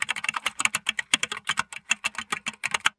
Keyboard Typing
Rapid mechanical keyboard typing with satisfying tactile clicks and spacebar thumps
keyboard-typing.mp3